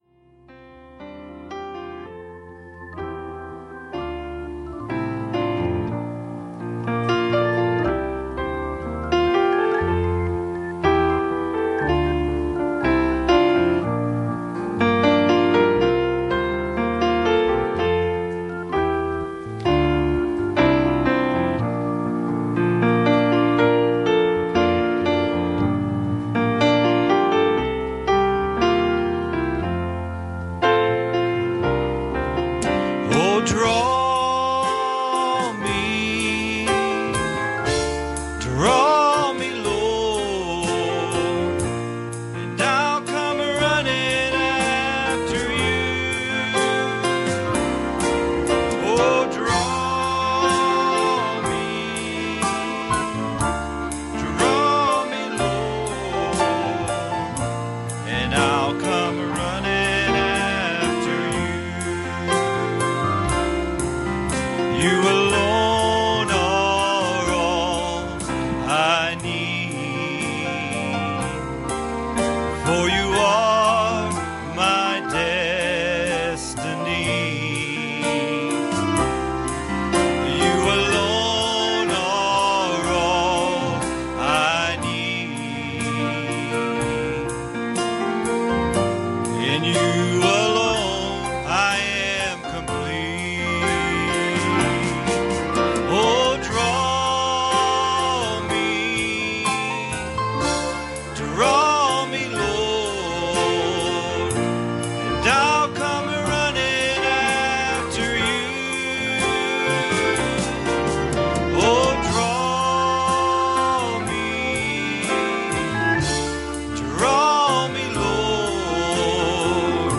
Passage: 1 Corinthians 12:4-11 Service Type: Wednesday Evening